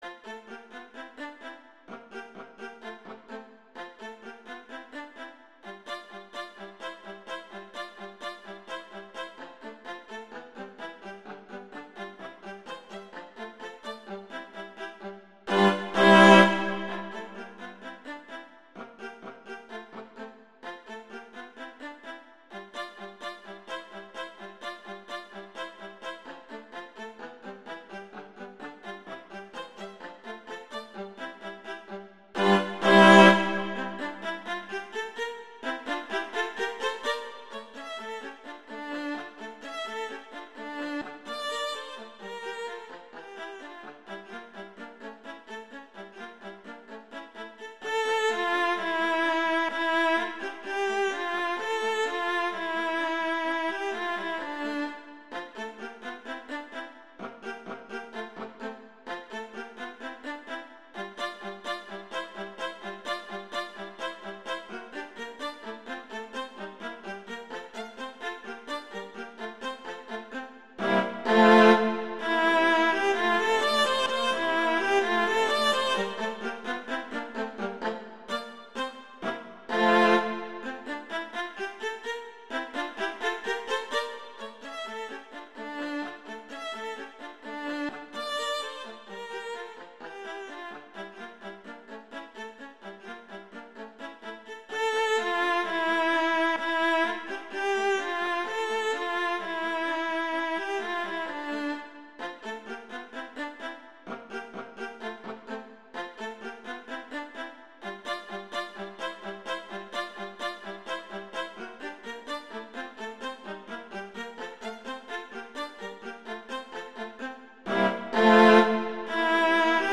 classical, french, children
F major, Bb major